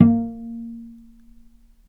vc_pz-A#3-mf.AIF